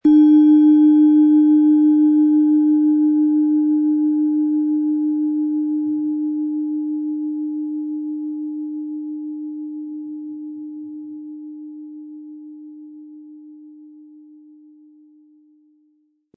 Eros & Mond Klangschale Ø 12,3 cm | Sound-Spirit im Sound-Spirit Shop | Seit 1993
Planetenton 1 Planetenton 2
• Mittlerer Ton: Mond
Im Sound-Player - Jetzt reinhören hören Sie den Original-Ton dieser Schale.
Mit einem sanften Anspiel "zaubern" Sie aus der Eros mit dem beigelegten Klöppel harmonische Töne.
MaterialBronze